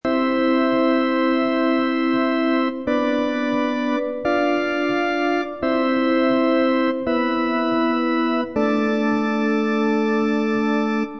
organ.mp3